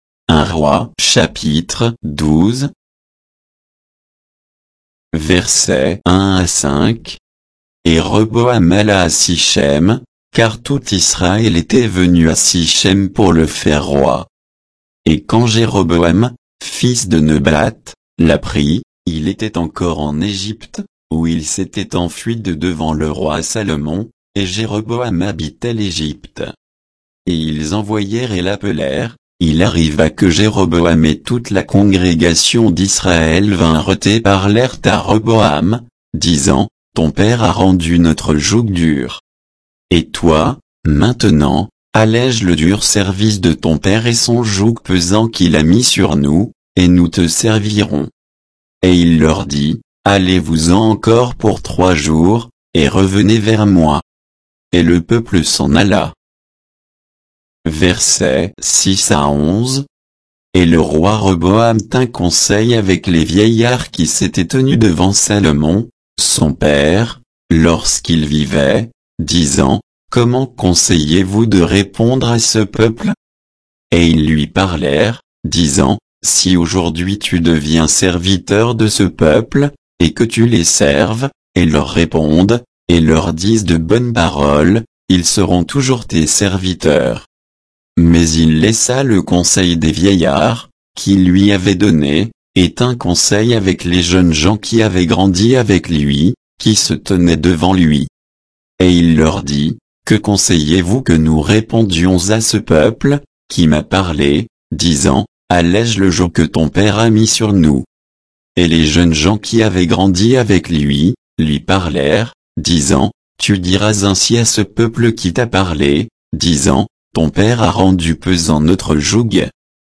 Bible_1_Rois_12_(sans_notes,_avec_indications_de_versets).mp3